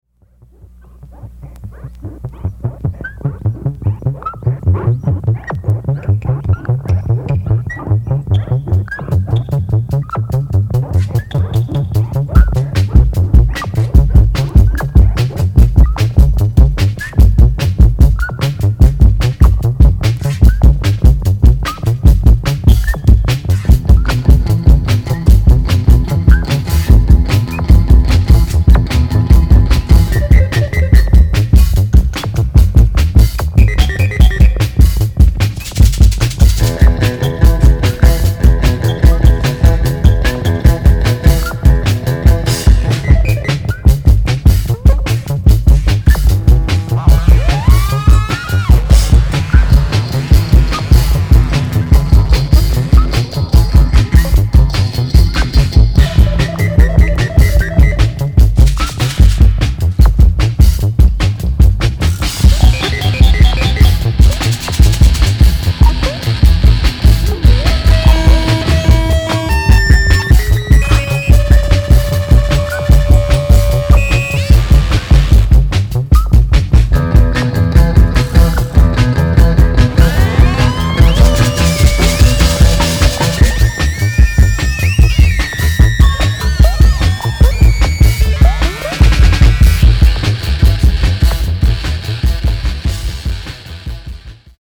No Waveの行方にも重大な影響を与えたと思われる、強迫的反復で迫ってくるゴリゴリのハードコア逸脱シンセパンク。
キーワード：ミニマル　乙女　宅録　脱線パンク